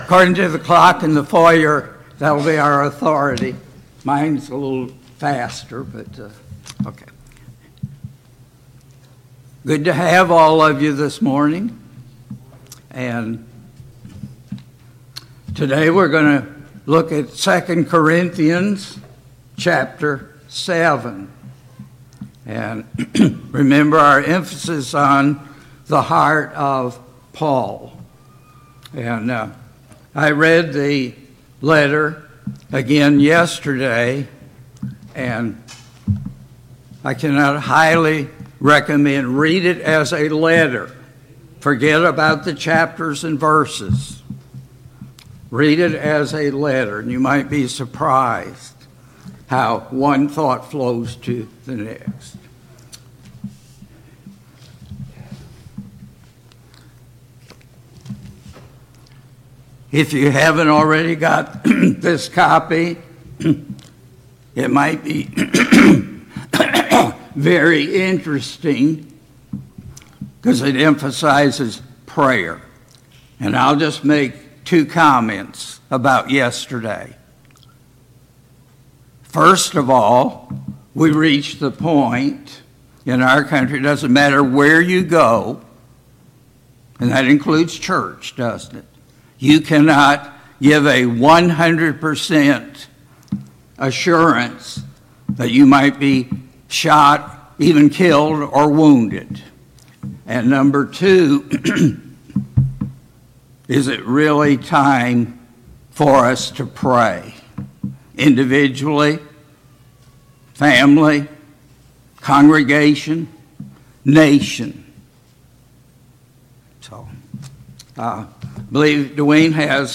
A Study of 2 Corinthians Passage: 2 Corinthians 7 Service Type: Sunday Morning Bible Class « 15.